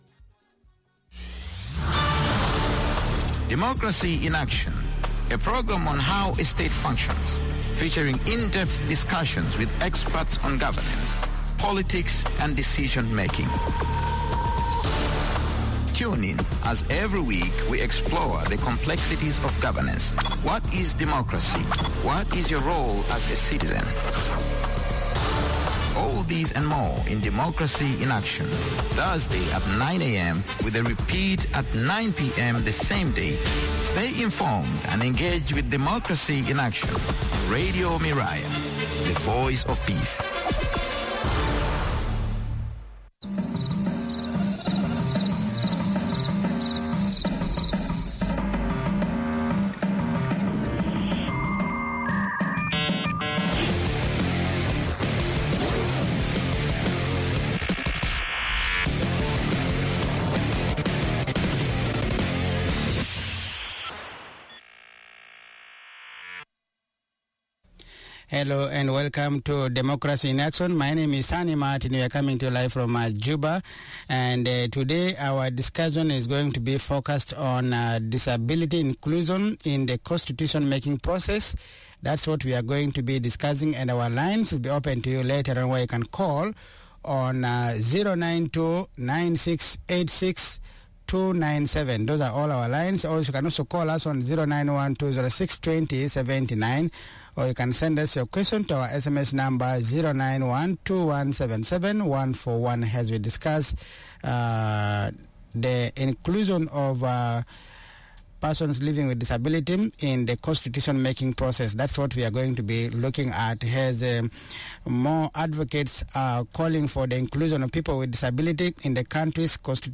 is in conversation with